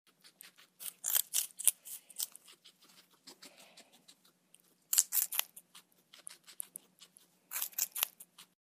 Звук морской свинки, поедающей траву